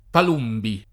[ pal 2 mbi ]